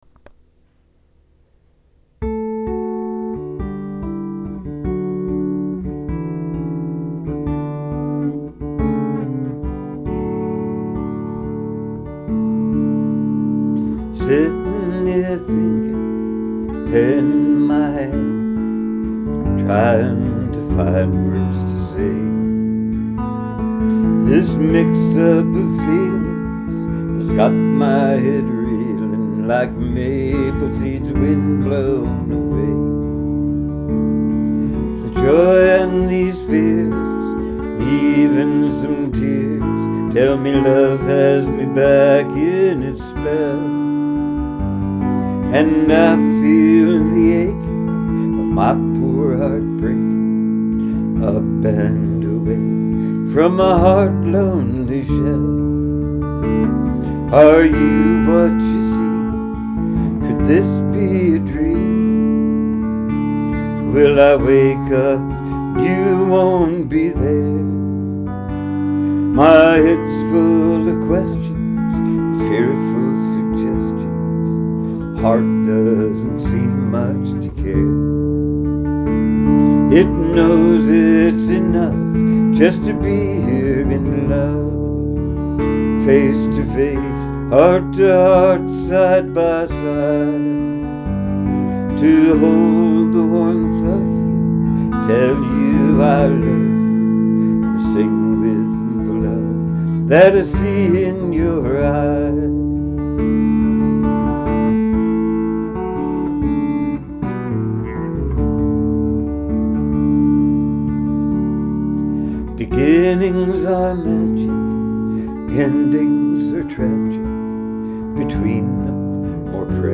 mostly just me and my guitars.